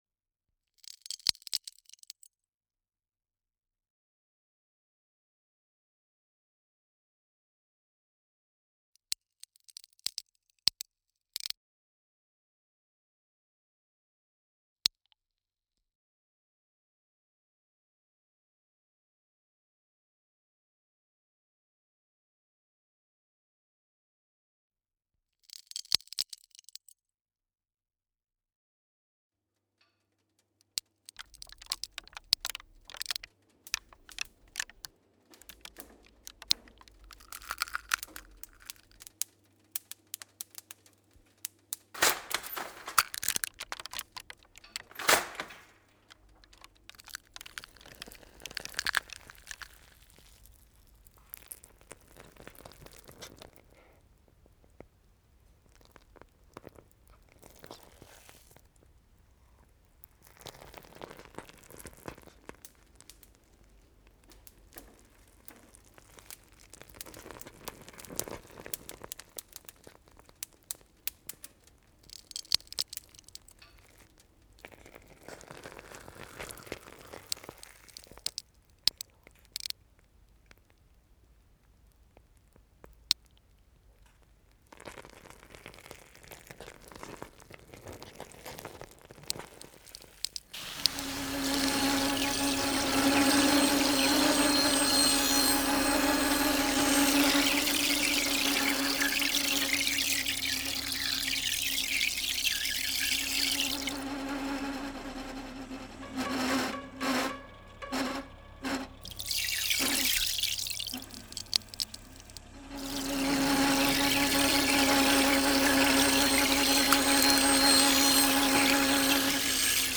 Experimentelles Radio